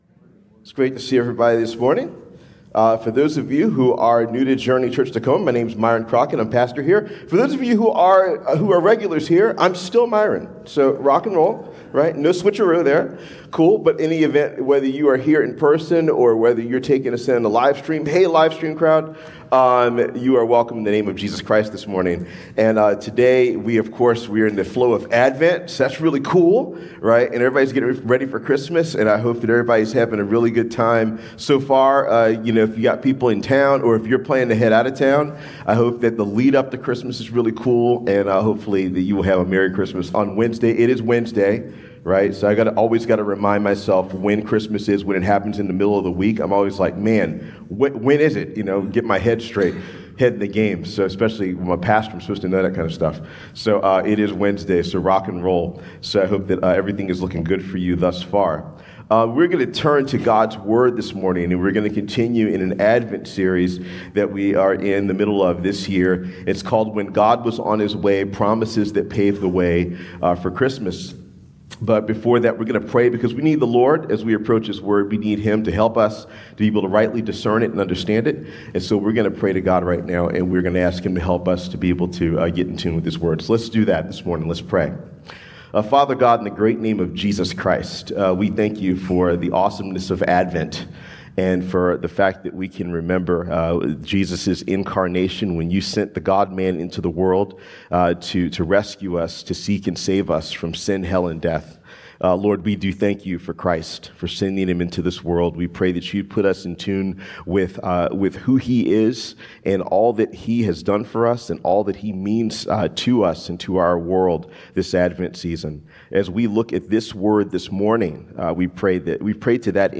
Sermons | Journey Community Church